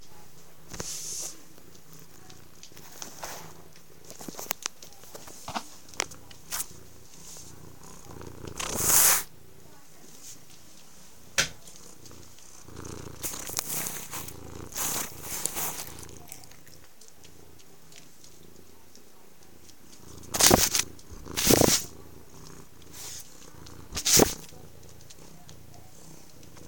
Tabitha can't half pur when she wants.